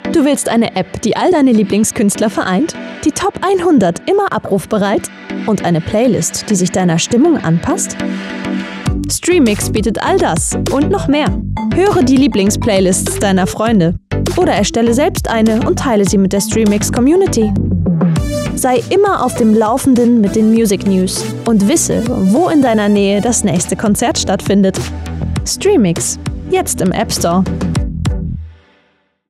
Mal warm, mysteriös oder erotisch, mal quirlig, jung und spritzig, sehr wandelbar.
Sprechprobe: Werbung (Muttersprache):
Multi-faceted young voice Trained actress with her own Soundstudio warm, mysterious, erotic or young, dynamic and lively